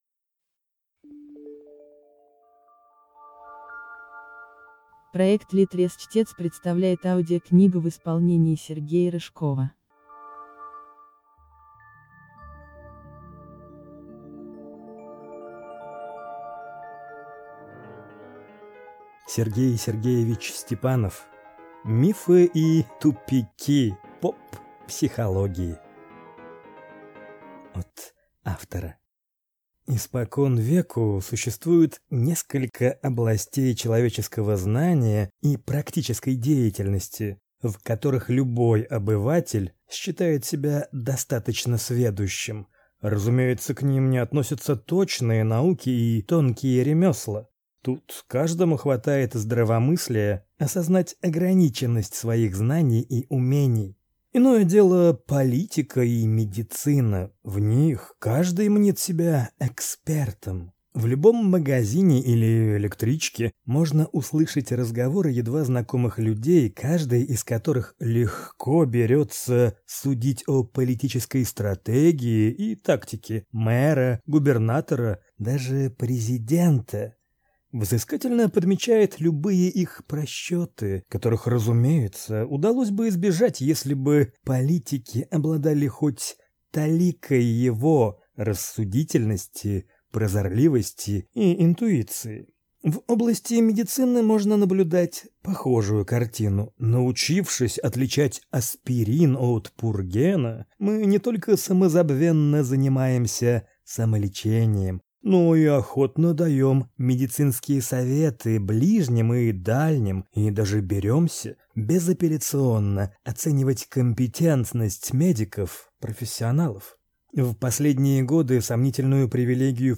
Аудиокнига Мифы и тупики поп-психологии | Библиотека аудиокниг